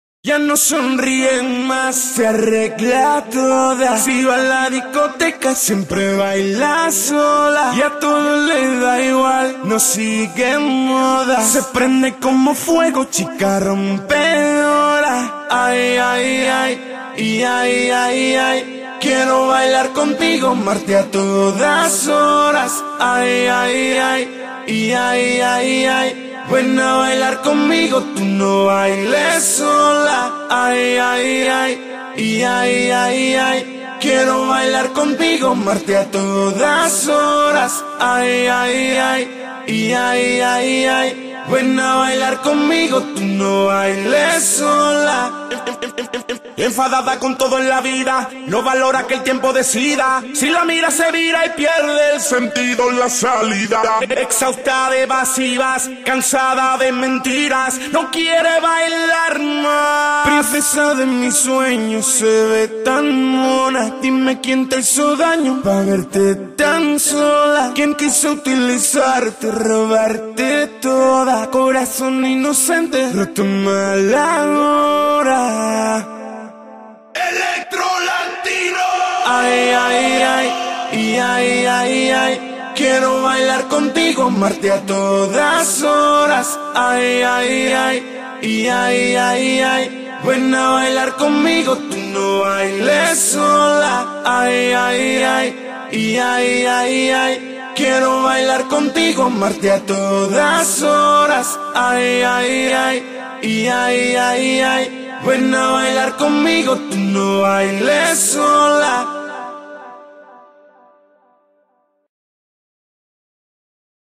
Официальная акапелла для вас!